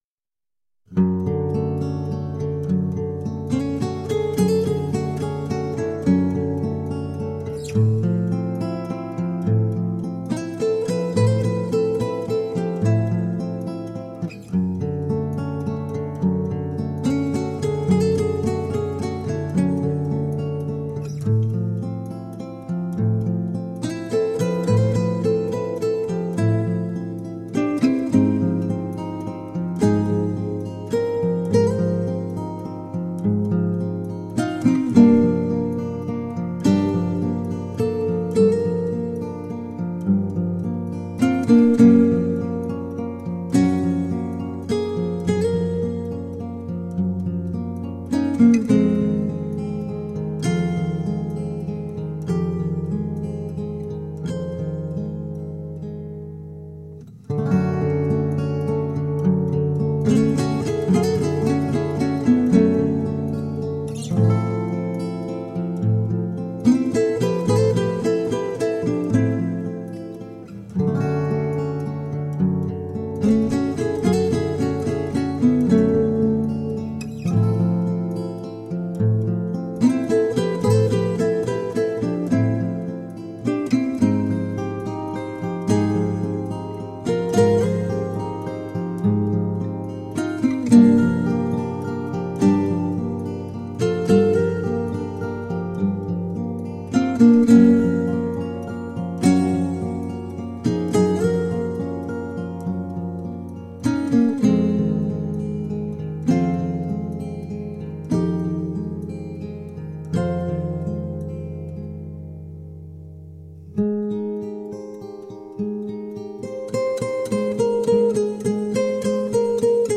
Layers of lush acoustic guitar.